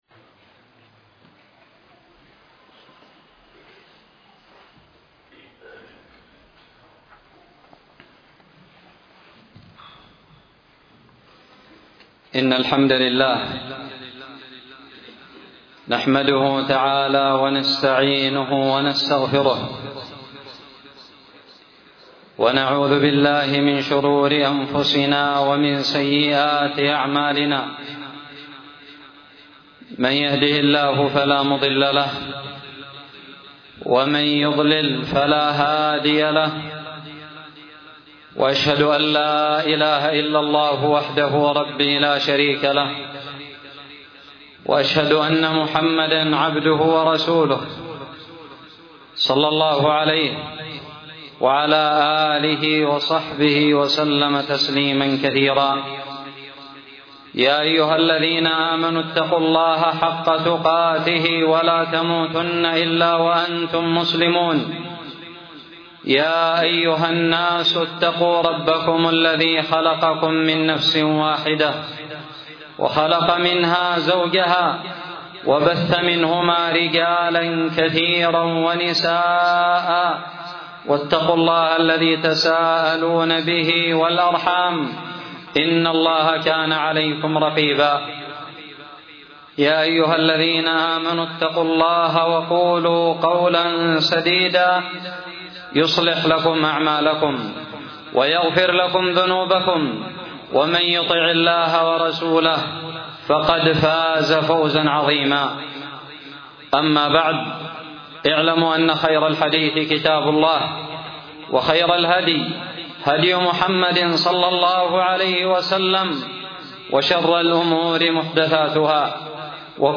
خطب الجمعة
ألقيت بدار الحديث السلفية للعلوم الشرعية بالضالع في عام 1439هــ